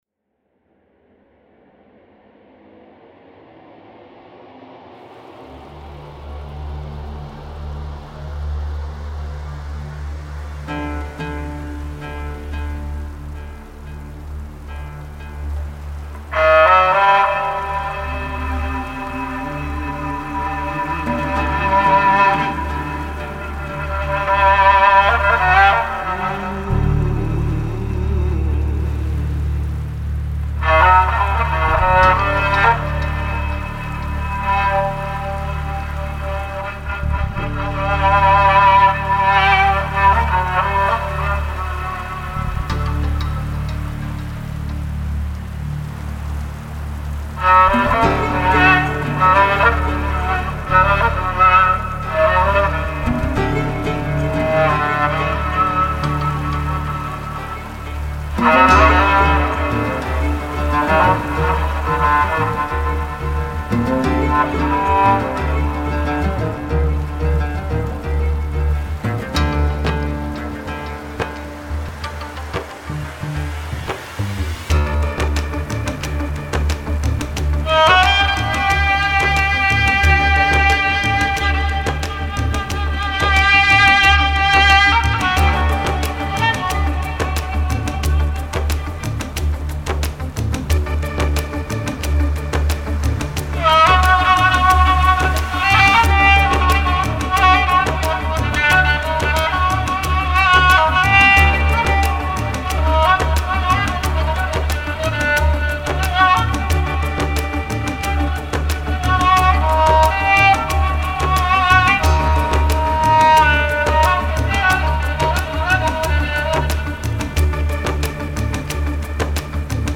آهنگ تیتراژ